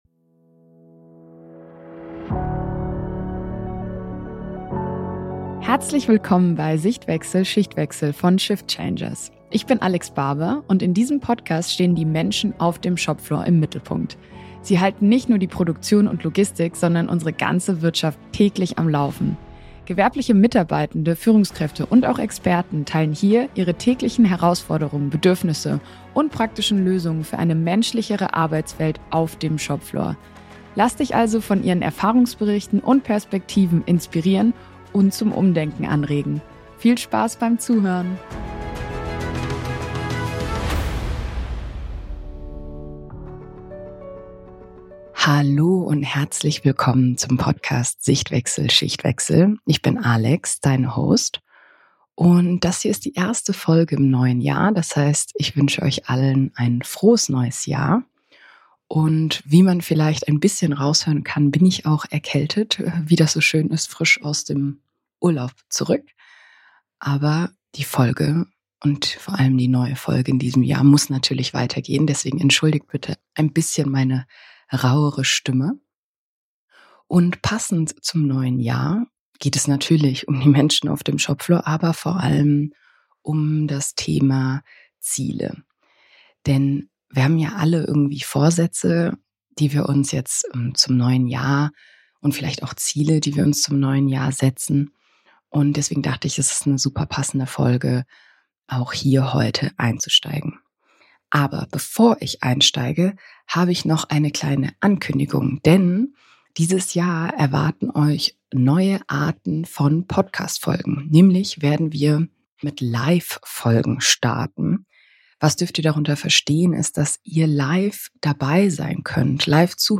Denn egal ob persönliche Entwicklung, Teamspirit oder betriebliche Kennzahlen: Ziele funktionieren nur dann, wenn sie realistisch, gemeinsam entwickelt und regelmäßig reflektiert werden. In dieser Solo-Folge erfährst du: